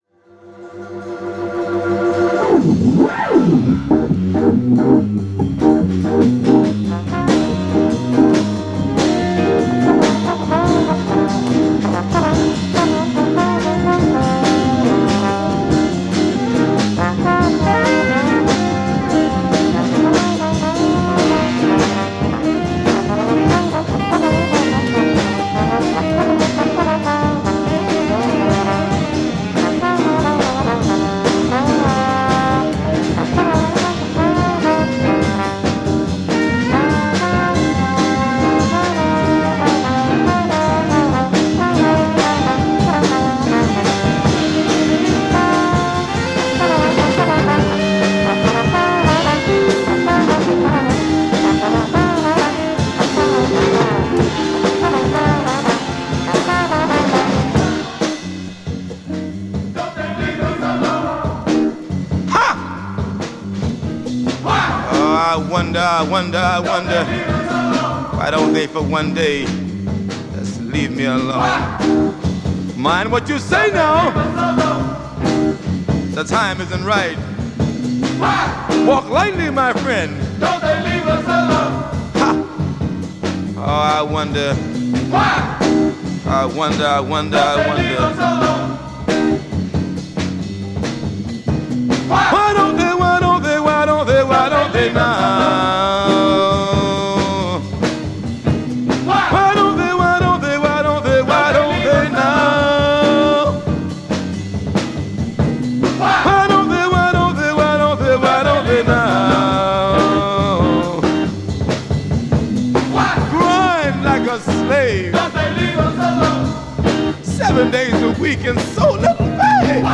全編混沌とした深みがあり迫力あります。